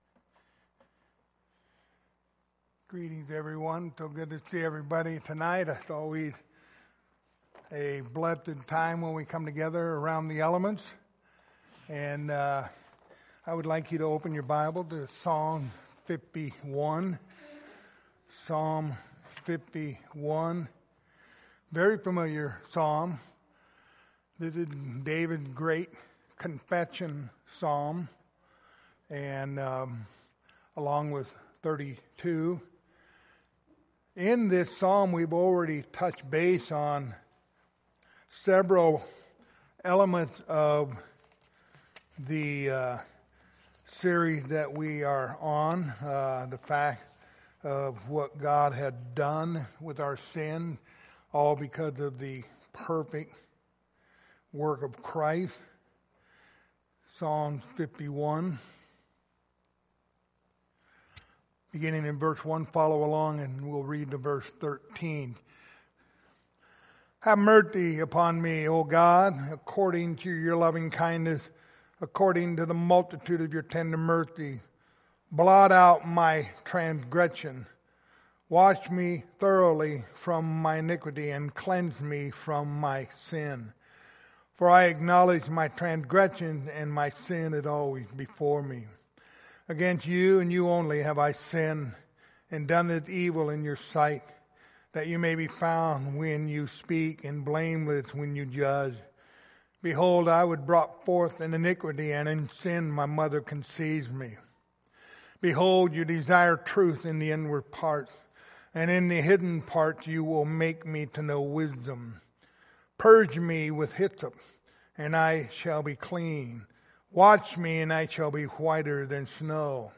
Lord's Supper Passage: Psalms 51:1-13 Service Type: Lord's Supper Topics